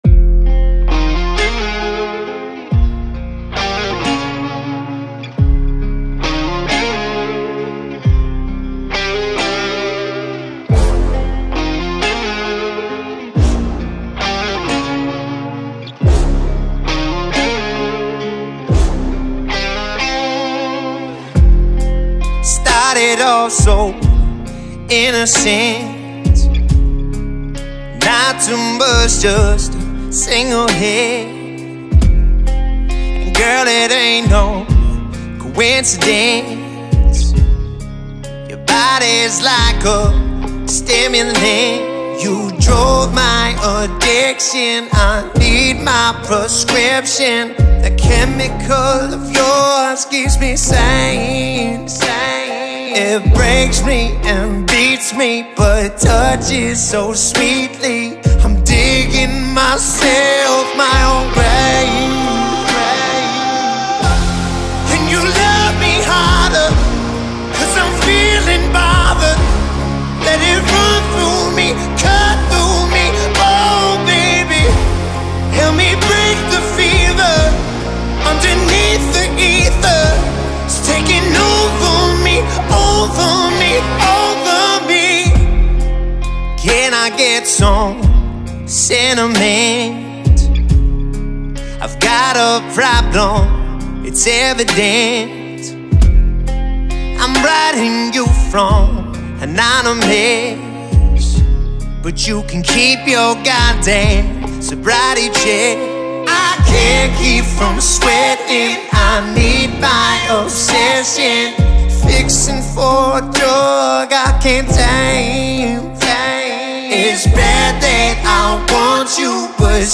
R&B و سول